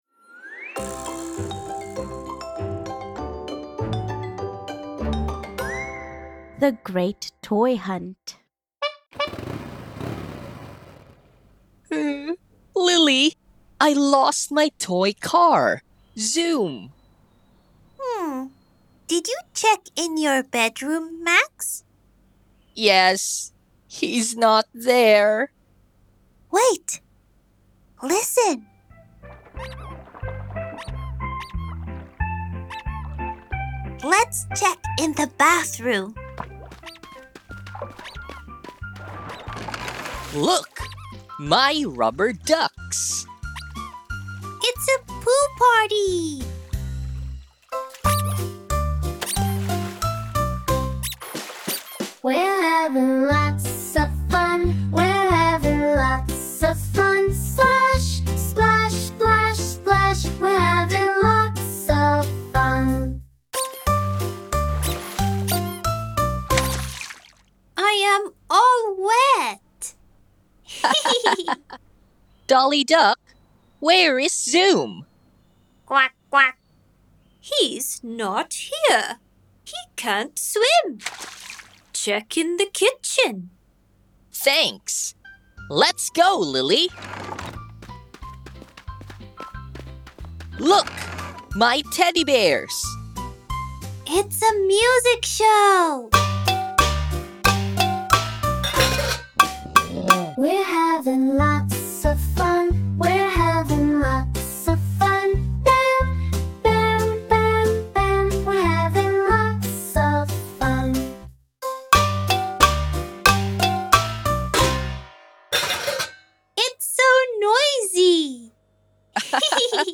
Odkryj magię nauki z angażującymi audiobookami od Novakid!
00.-The-Great-Toy-Hunt-story.mp3